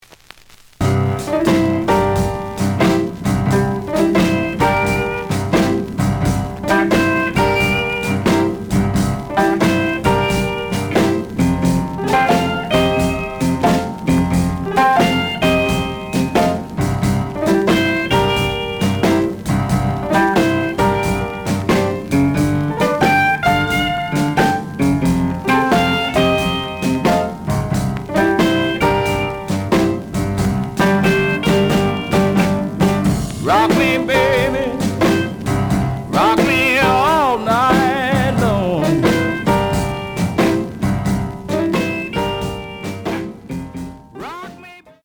The audio sample is recorded from the actual item.
●Genre: Blues